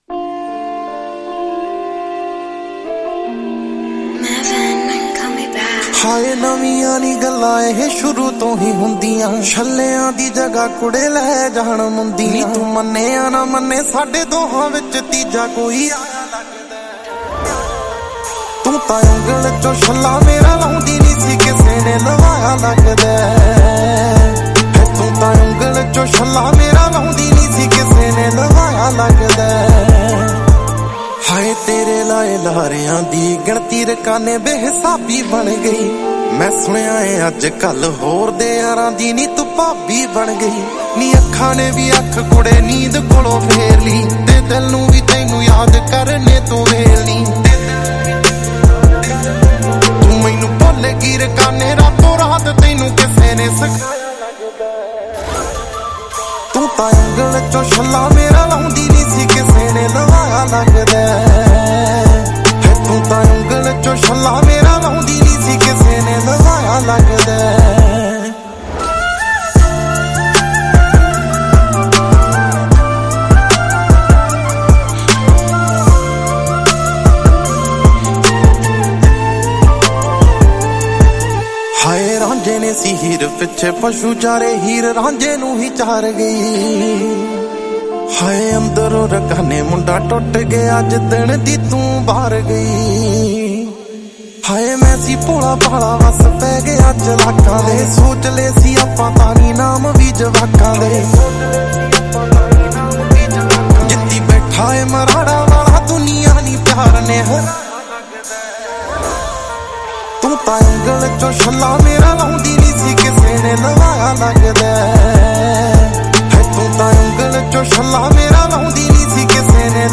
Category: Punjabi Album